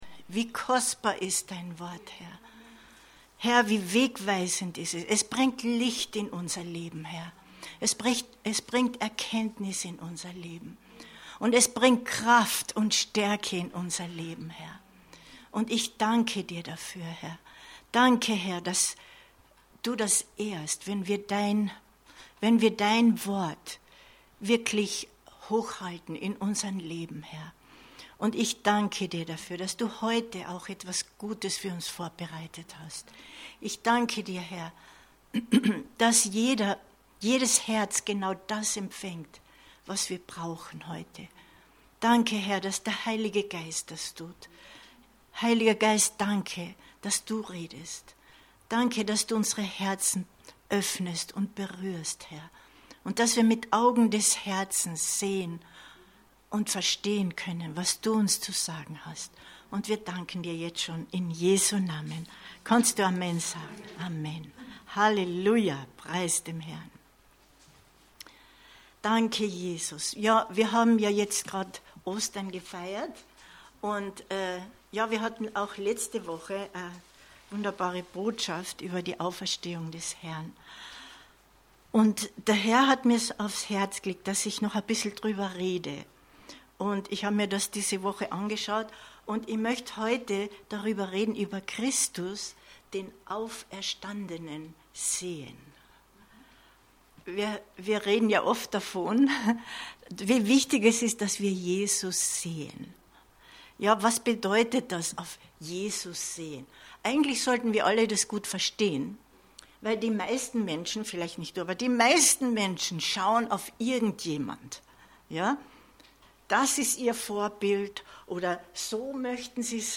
Christus den Auferstandenen sehen 24.04.2022 Predigt herunterladen